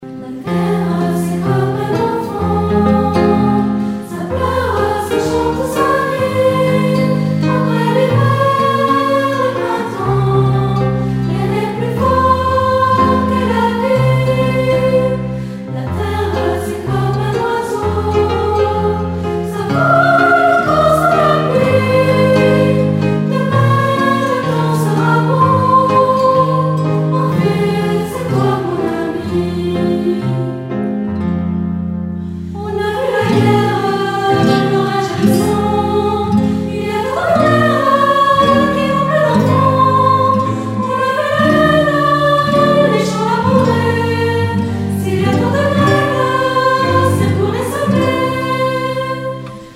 1994 - 1995 - Choeur d'enfants La Voix du Gibloux
La VDG a la chance de participer au spectacle du chœur-mixte de Villarlod intitulé « Orphée, mon ami ».